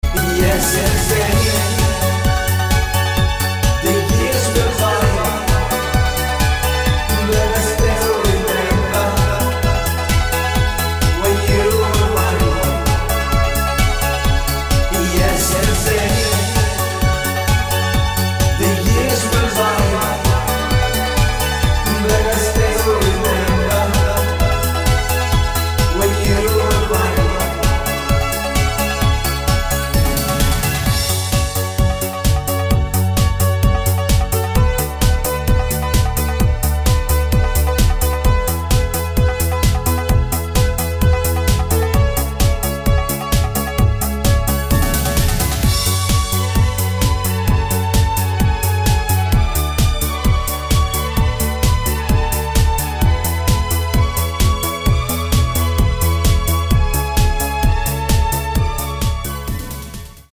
мужской вокал
диско
Italo Disco
dance
электронная музыка
итало-диско